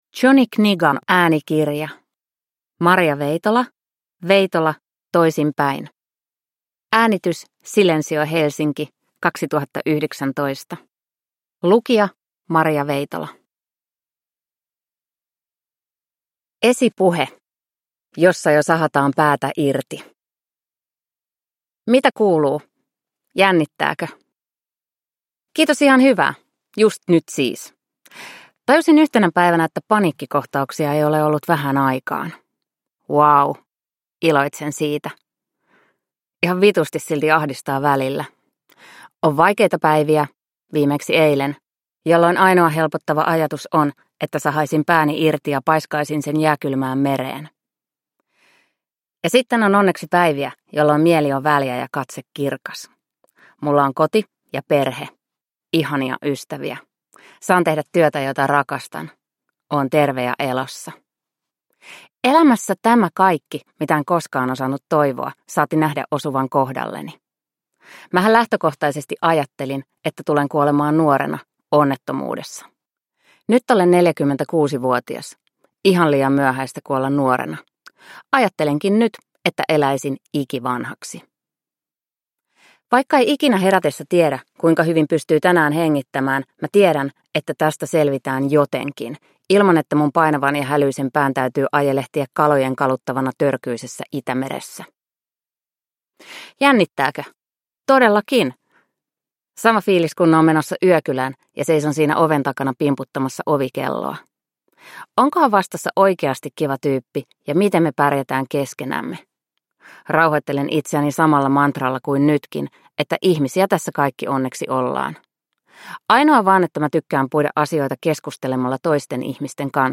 Toisinpäin – Ljudbok – Laddas ner
Uppläsare: Maria Veitola